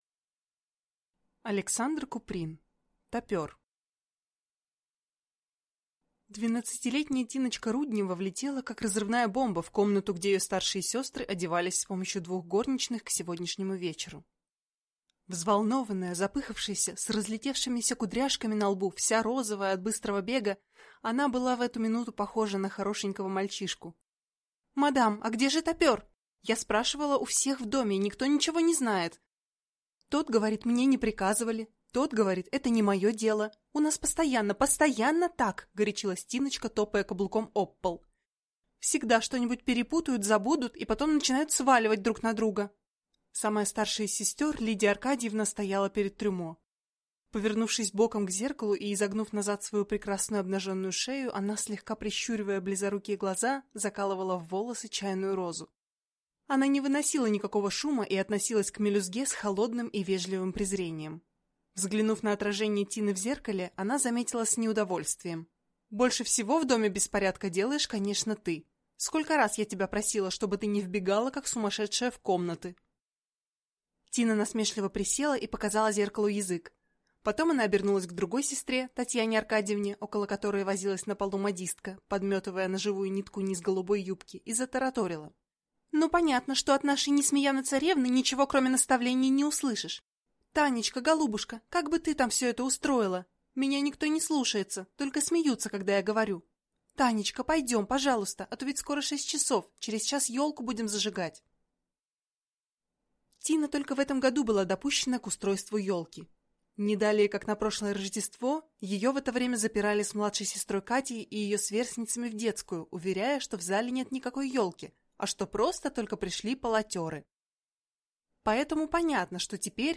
Аудиокнига Тапер | Библиотека аудиокниг